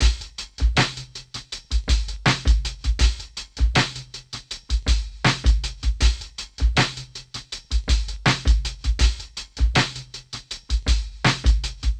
bimmer80bpm.wav